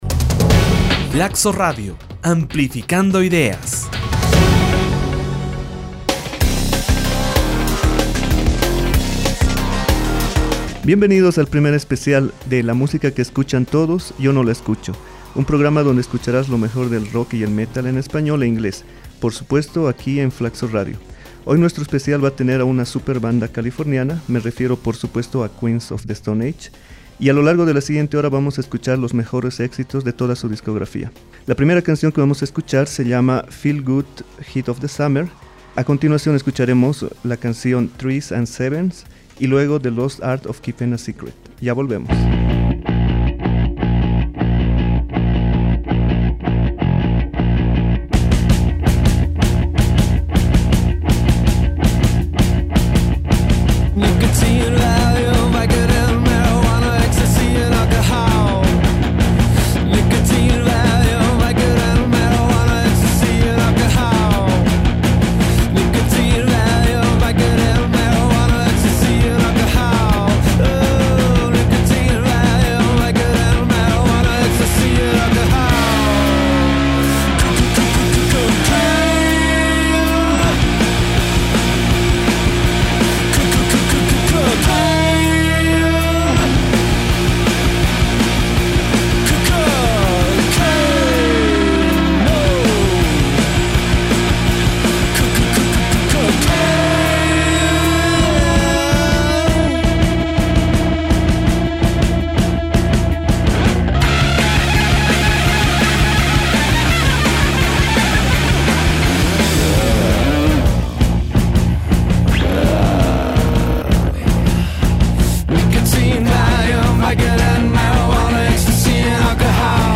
Queens of the stone age es una banda californiana de stoner rock.